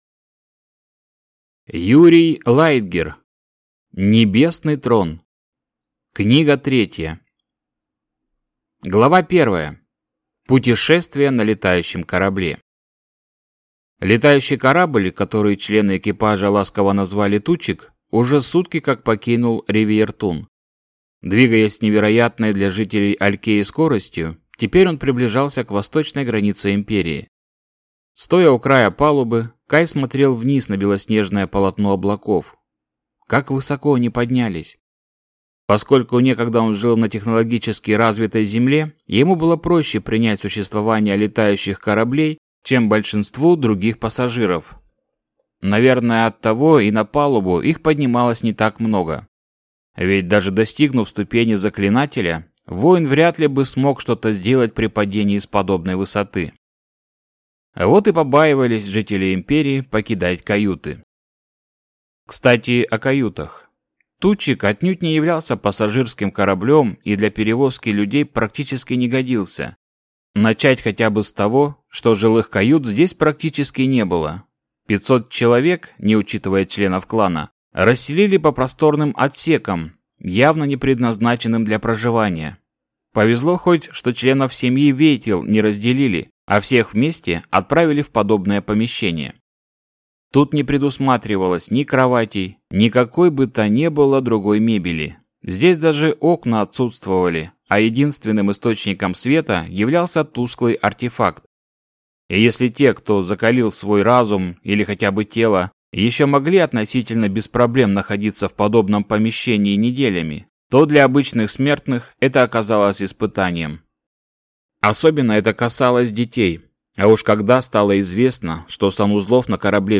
Аудиокнига Небесный Трон. Книга 3 | Библиотека аудиокниг